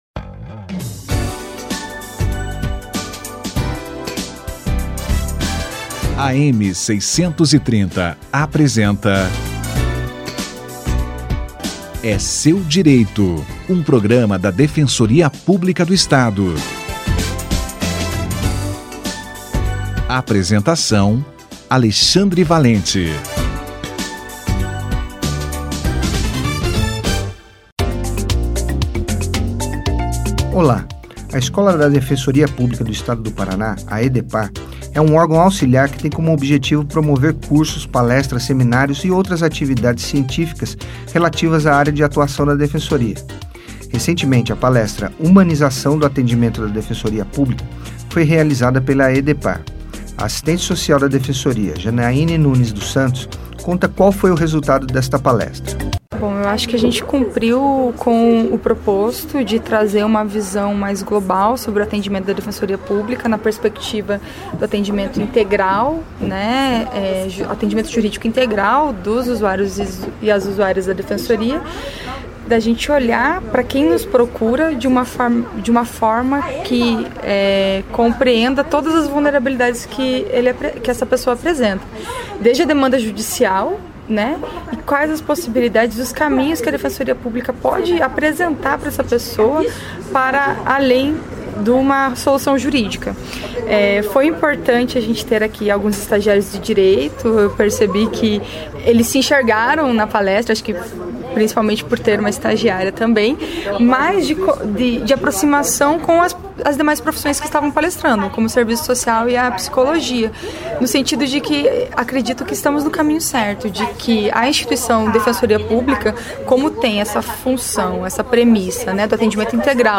Áreas de atuação da Defensoria Pública - Entrevista